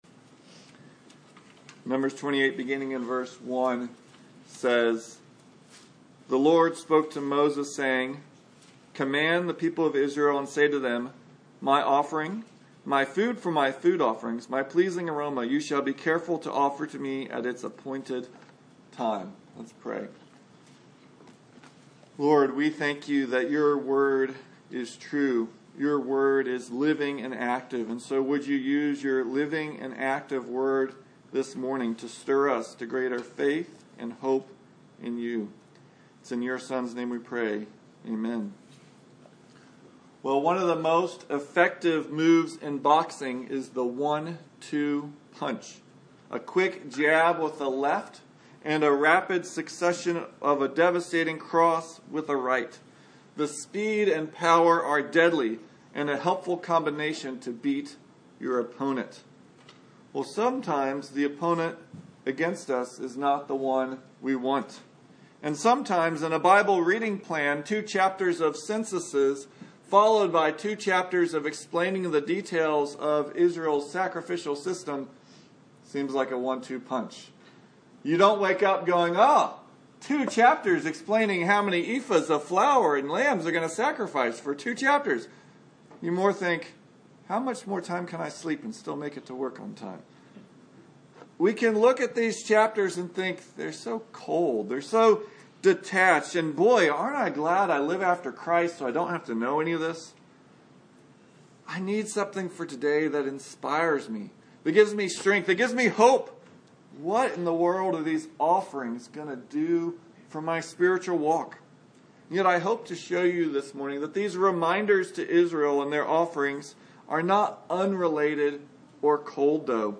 Numbers 28-29 Service Type: Sunday Morning Reading through the Bible can be tough when you get to Numbers.